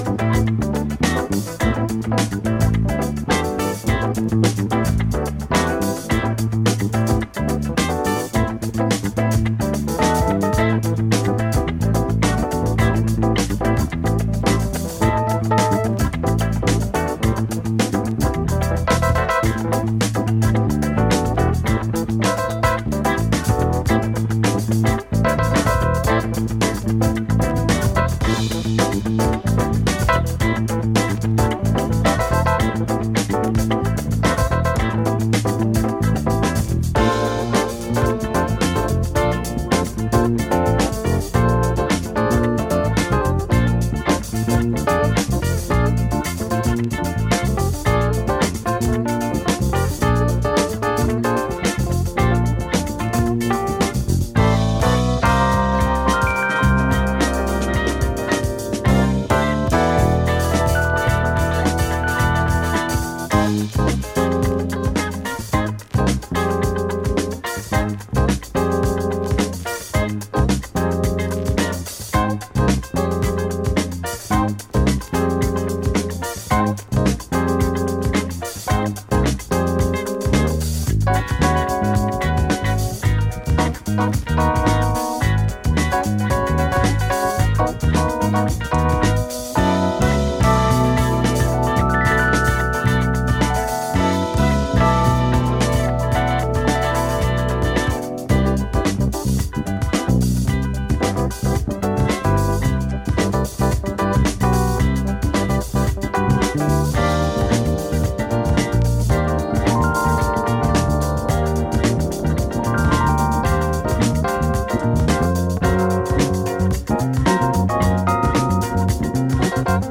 All time classic jazz funk LP from the American organist
Funk / soul, Fusion
Jazz-funk